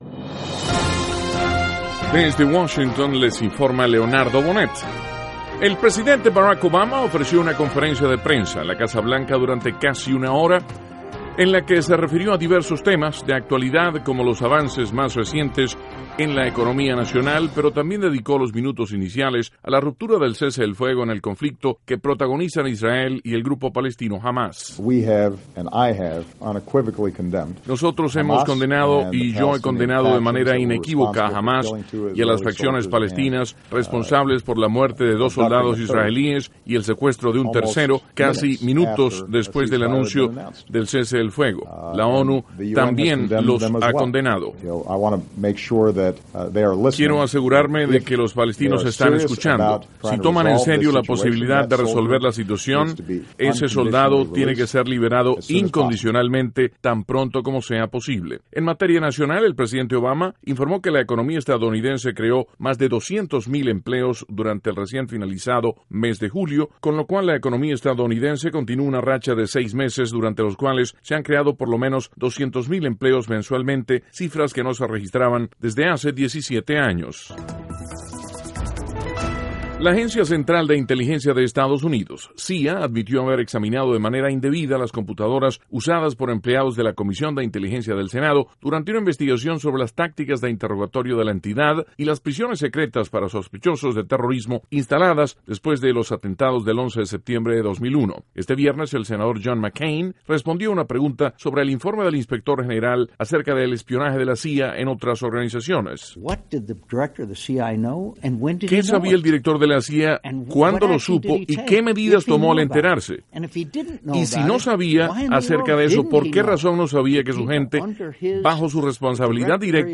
NOTICIAS - VIERNES, PRIMERO DE AGOSTO, 2014